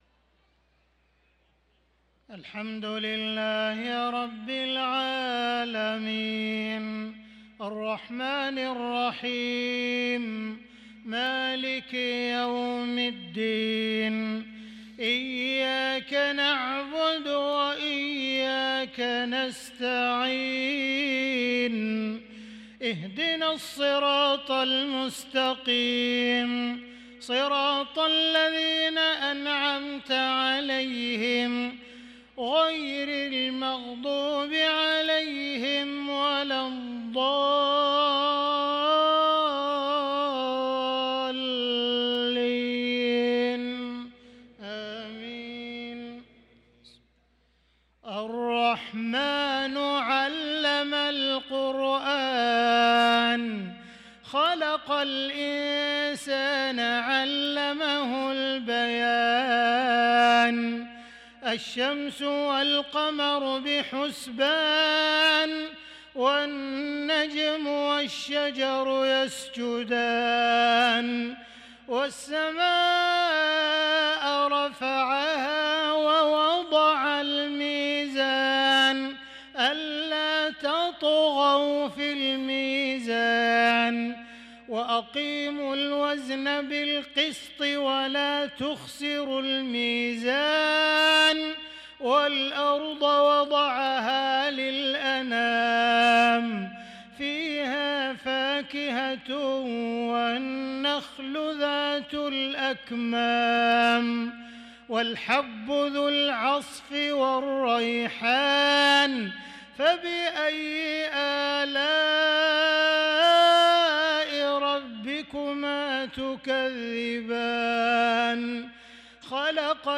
صلاة العشاء للقارئ عبدالرحمن السديس 20 رجب 1445 هـ
تِلَاوَات الْحَرَمَيْن .